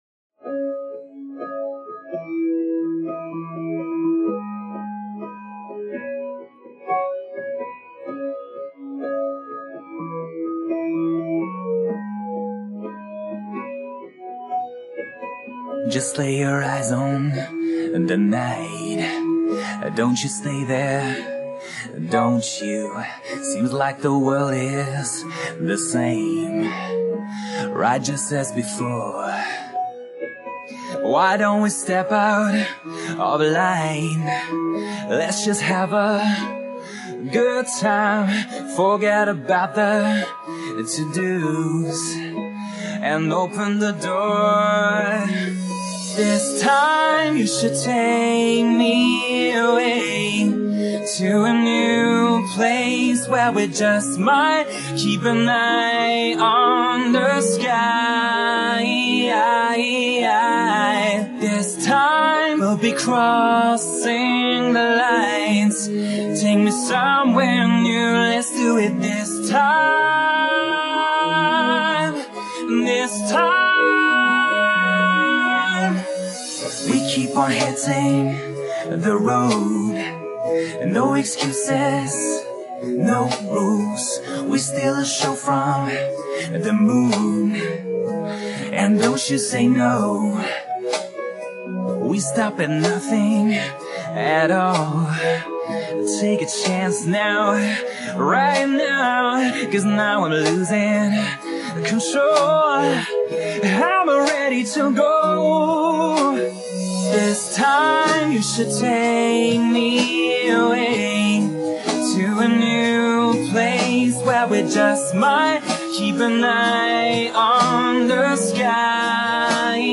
Категория: Скачать Зарубежные акапеллы
nice acapella